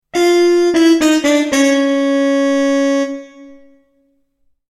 Sad Muted Trumpet Sound Effect
Description: Sad muted trumpet sound effect. A recognizable muted trumpet melody that signals failure in a game, video clip, or quiz show. Perfect sound effect for funny moments, wrong answers, or comedic fails.
Sad-muted-trumpet-sound-effect.mp3